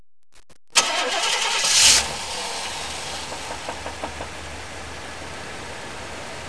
Knock, Knock. Uh-Oh.
knocking because he wants to come out and play. It goes away in the recording, but comes back after a couple minutes of idling.
knock1.wav